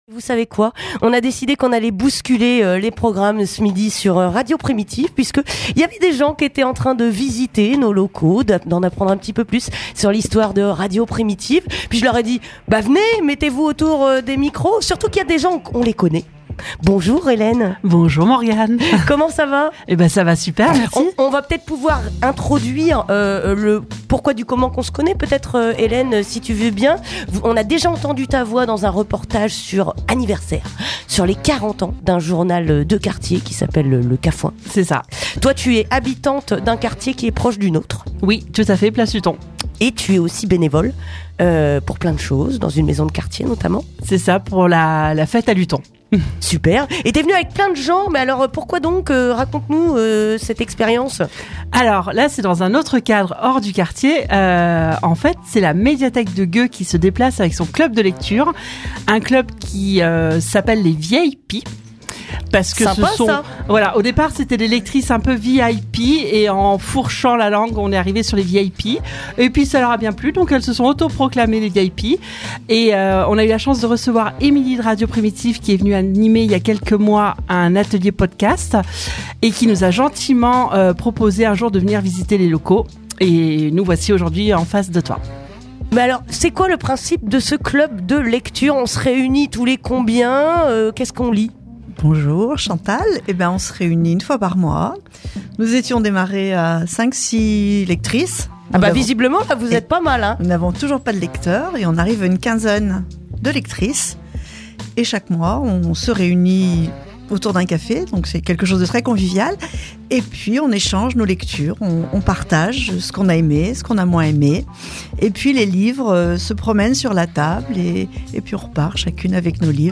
De passage dans nos studios, le club de lecture de la médiathèque de Gueux en a profité pour se présenter sur les ondes !